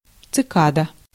Ääntäminen
Ääntäminen France: IPA: /si.ɡal/ Haettu sana löytyi näillä lähdekielillä: ranska Käännös Konteksti Ääninäyte Substantiivit 1. цикада {f} (tsikada) eläintiede Suku: f .